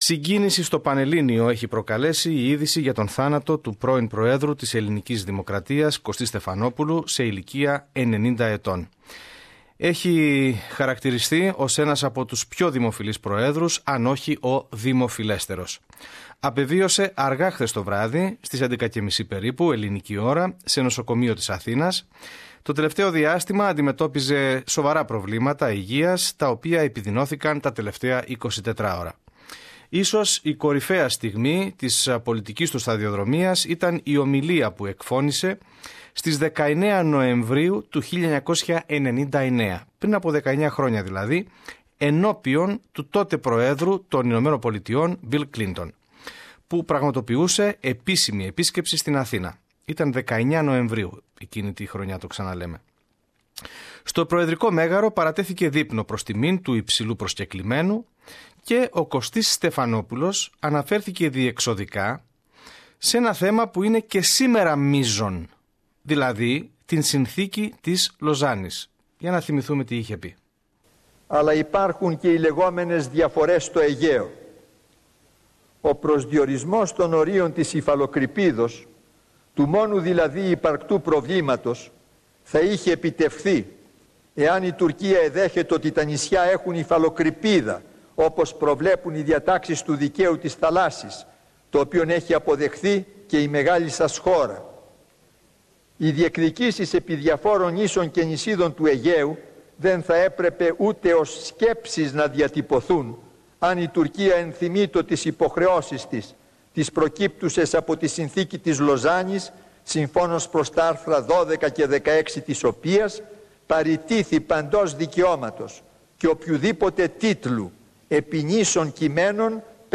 Η ιστορική ομιλία του Κωστή Στεφανόπουλου ενώπιον του Μπιλ Κλίντον